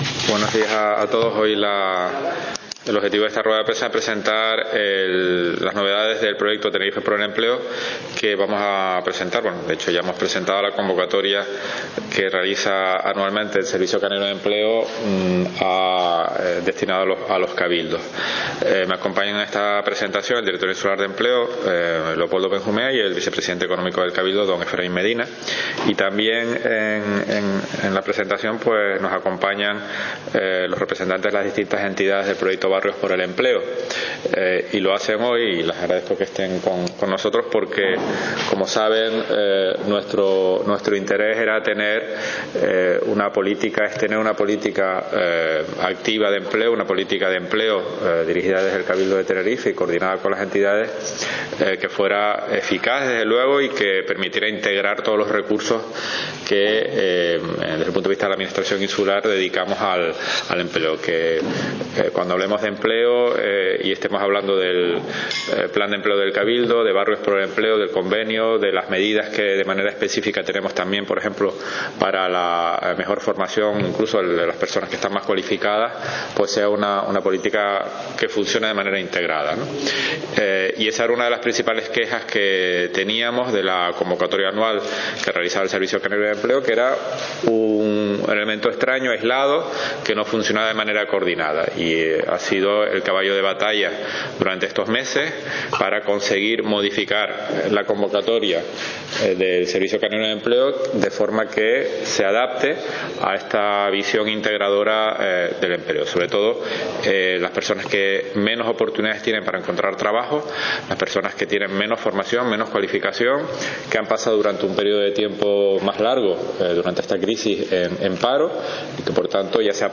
El presidente del Cabildo, Carlos Alonso, informó en rueda de prensa de las novedades del proyecto ‘Tenerife por el Empleo’ con los cambios solicitados por esta Corporación en las directrices del Servicio Canario de Empleo.